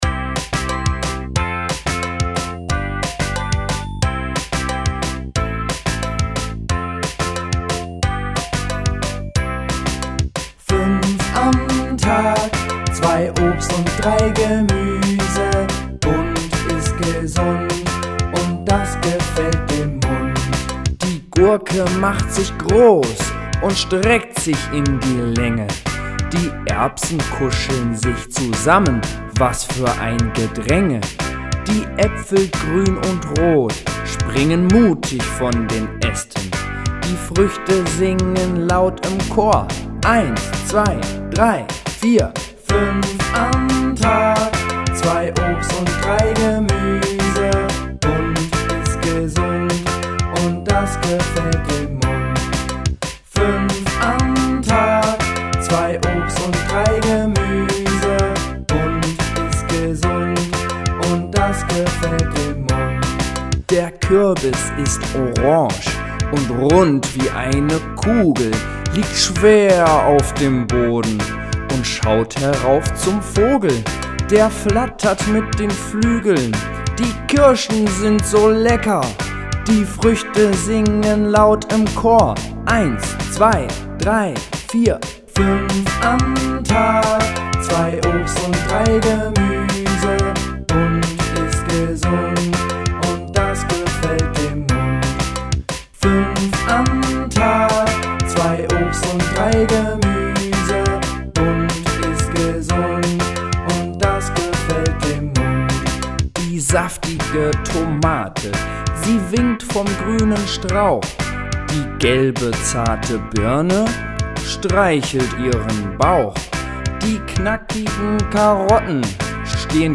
Lieder
5-bewegungslied-5amtag.mp3